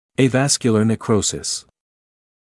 [ə’væskjələ nek’rəusɪs] [eɪ-][э’вэскйэлэ нэк’роусис] [эй-]аваскулярный некроз